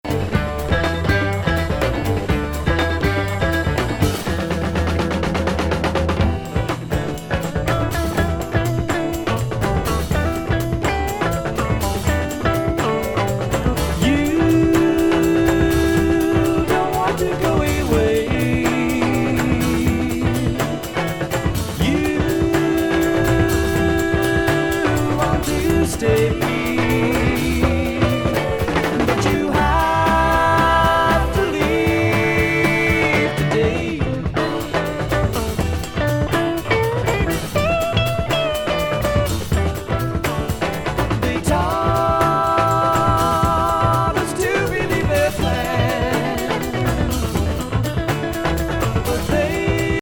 サロッド
タブラ
エスノ・グルーヴィ・ロック
サイケ・ロック・セッション